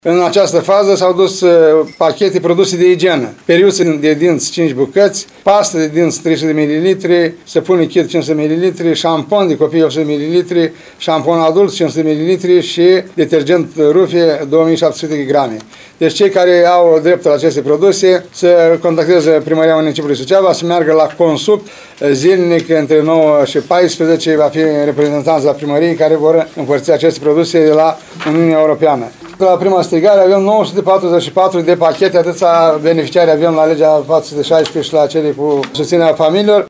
Primarul ION LUNGU a declarat că aproape 950 de localnici vor beneficia de articolele de igienă gratuite.